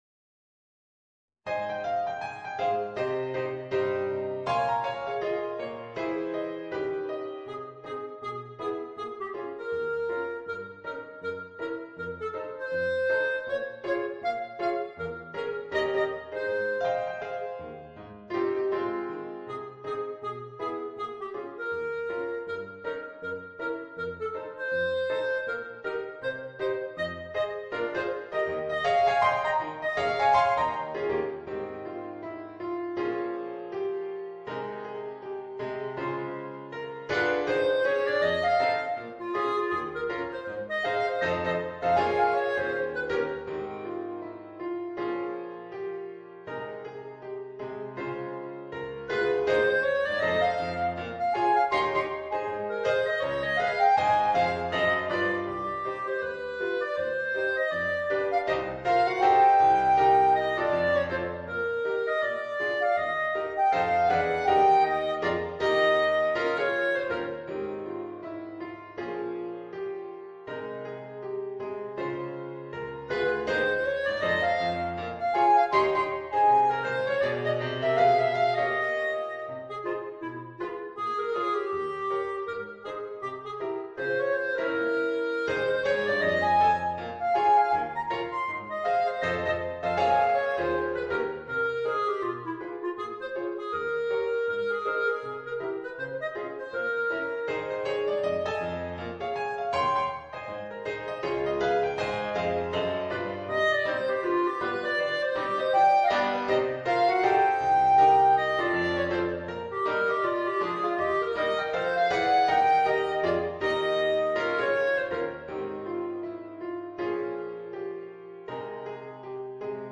Voicing: Clarinet and Piano